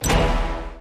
Appear_Total_Win_Sound.mp3